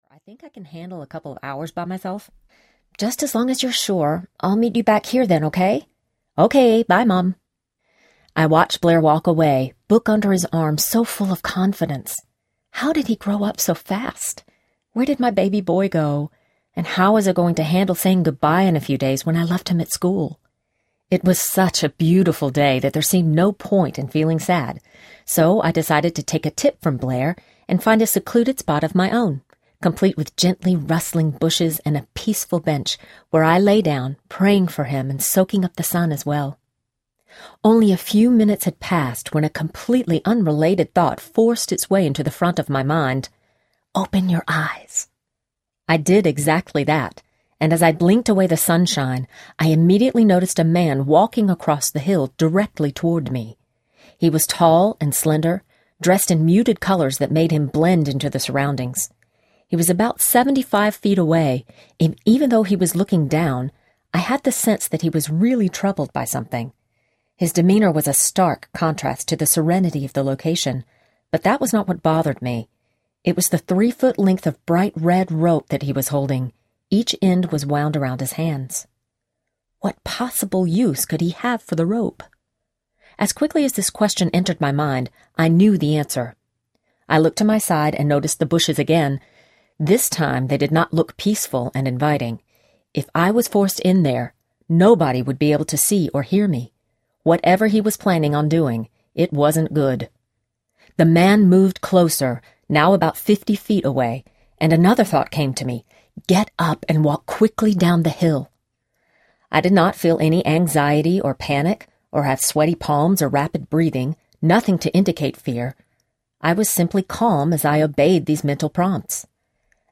It’s a God Thing Audiobook
5.2 Hrs. – Unabridged